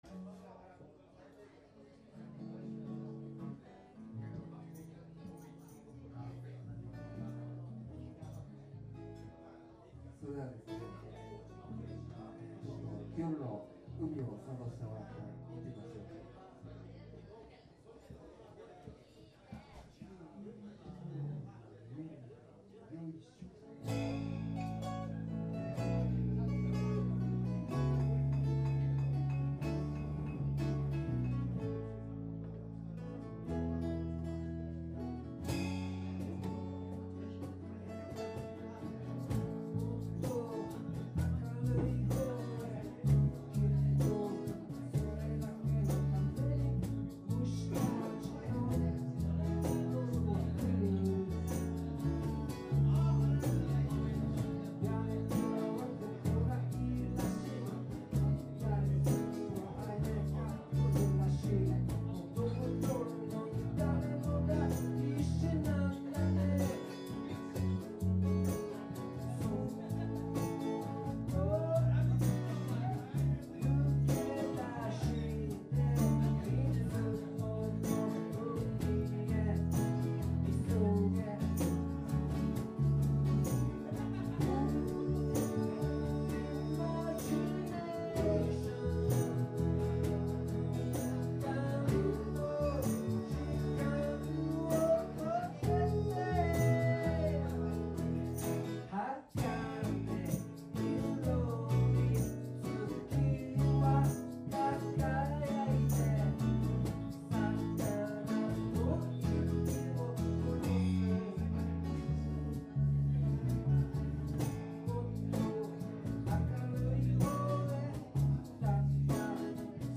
まず、アコースティック用のライブステージが有るんです。